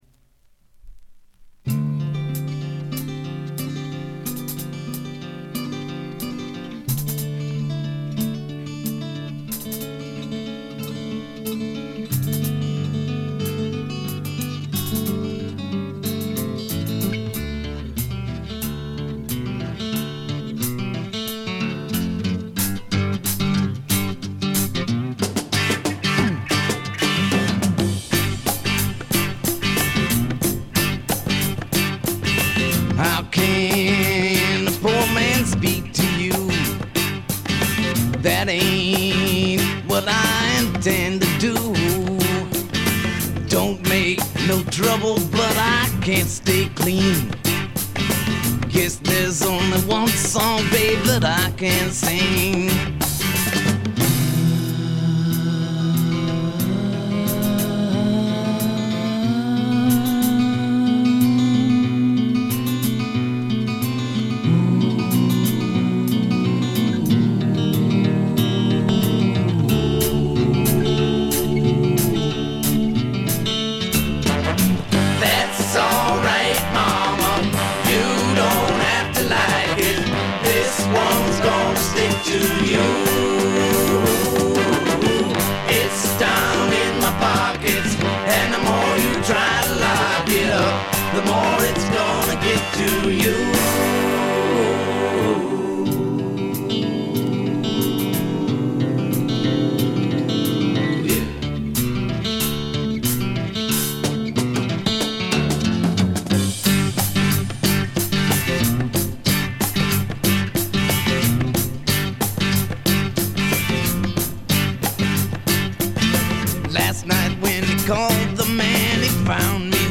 ごくわずかなノイズ感のみ。
試聴曲は現品からの取り込み音源です。
Congas